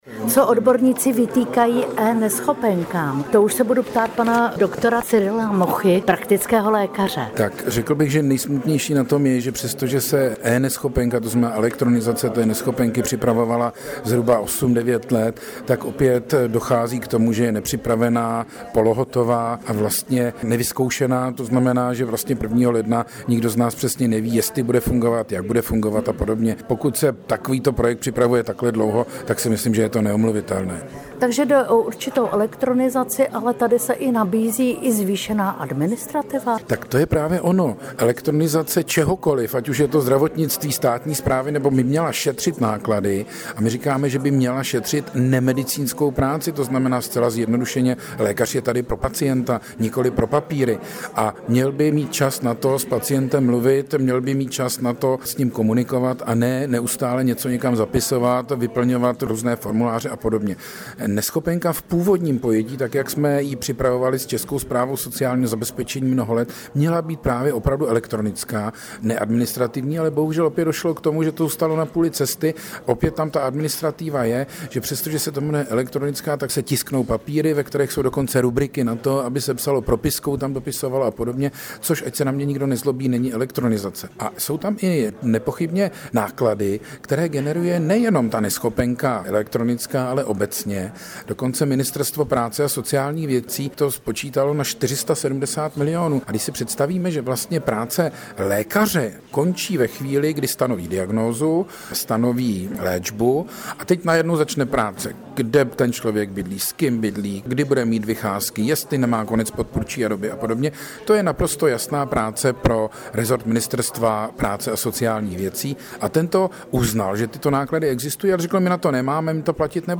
AUDIO rozhovor k tématu